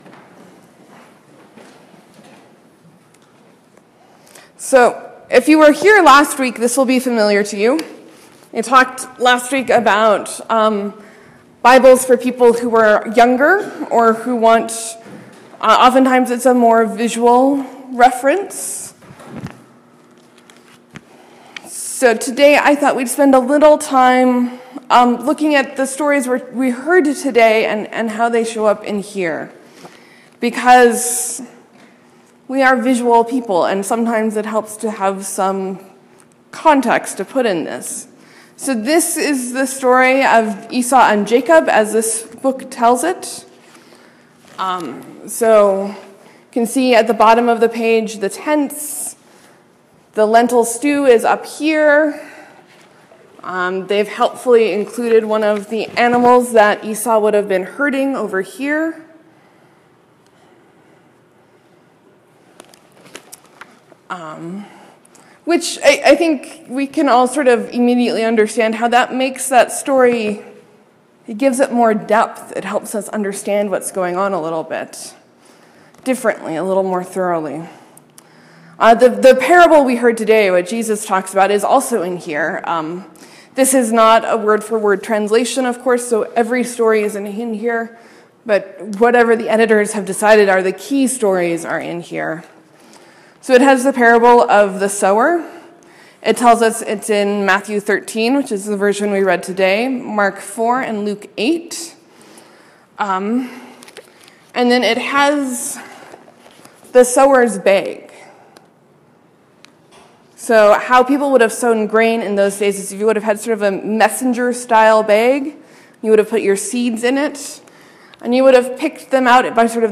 Sermon: What might this parable have sounded like if it was told today? What does the product of the seed in good ground need to hear?